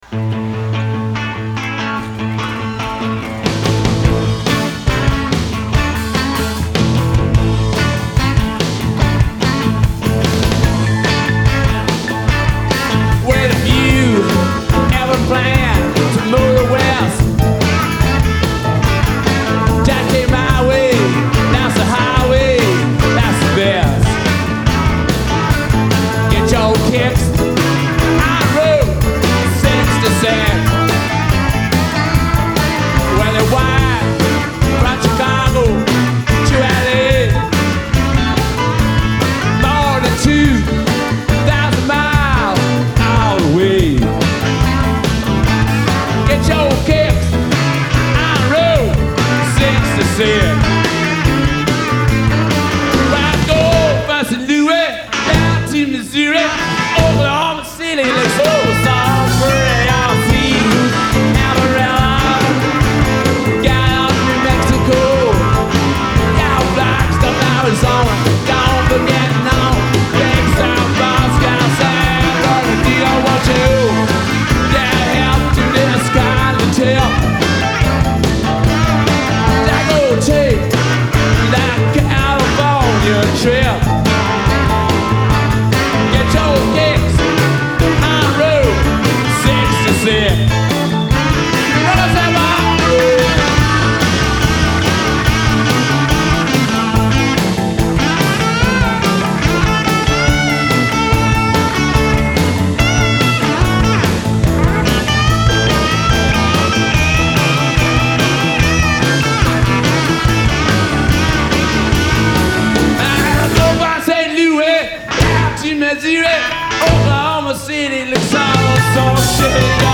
Genre : Pop, Rock